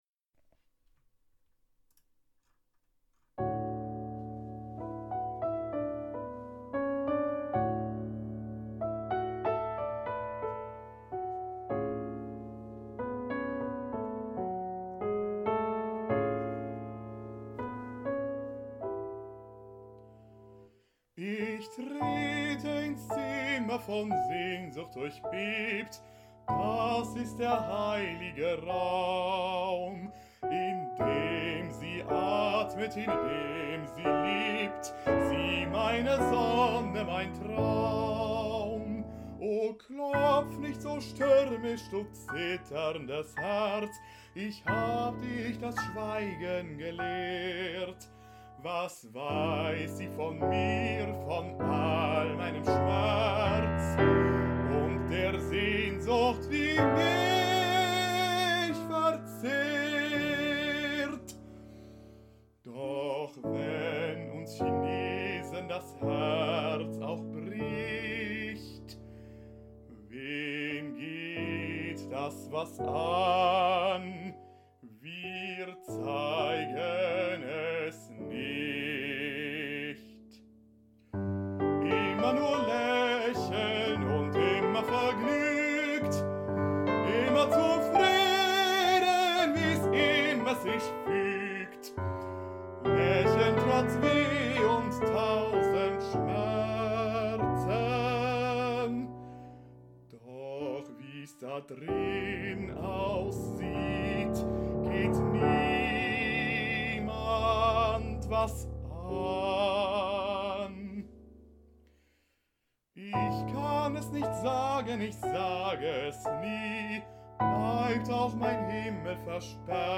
Ich singe klassisch seit knapp 10 Jahren, im Chor etwas länger, bin 27 Jahre alt und "Diagnose" baritonaler Tenor / dramatischer Tenor / mit Mitte dreißig vielleicht mal Heldentenor.